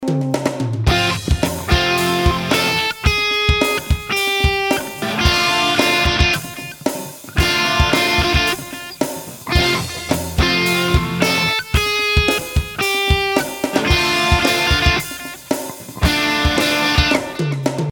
The melody has a little chromatic run, and I need suggestions for which chords to play behind it.